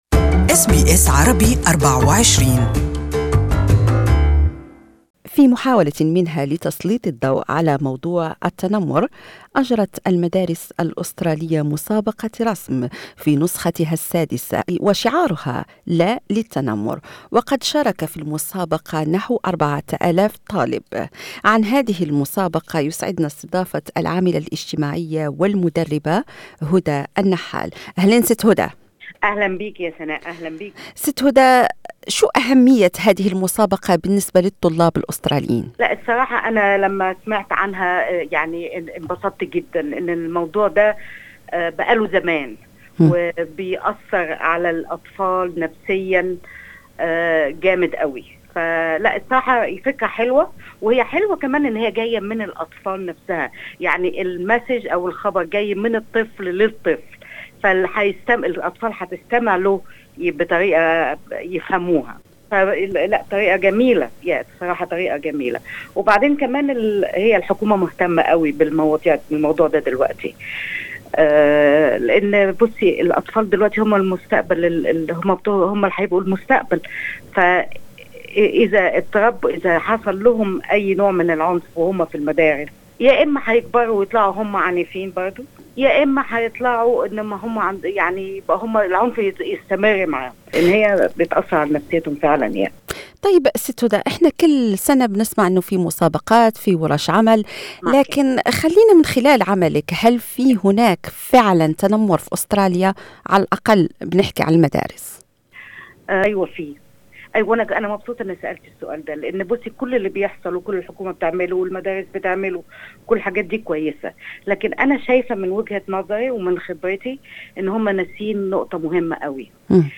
عن مدى أهمية المسابقة، وظاهرة التنمر في المدارس الأسترالية، التقينا بالموظفة الاجتماعية والمدربة